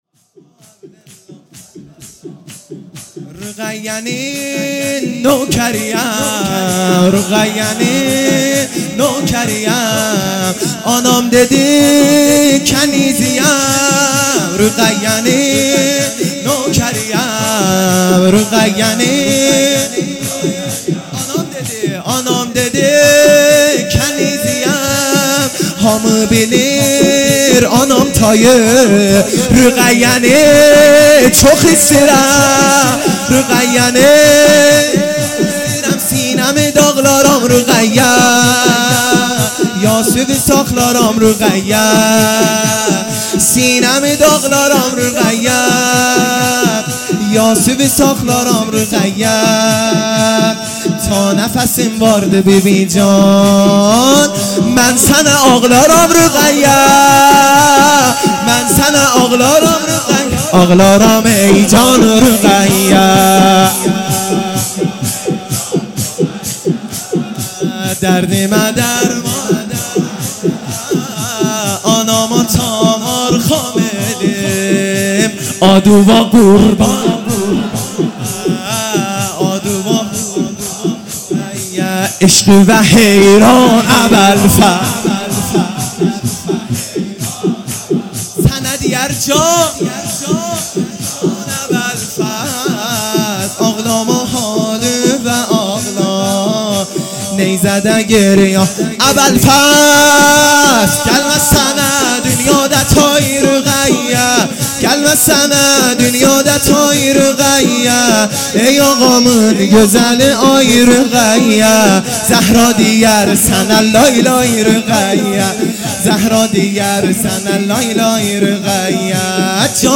شور ا رقیه نین نوکریم